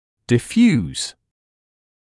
[dɪ’fjuːs][ди’фйуːс]рассеянный; диффузный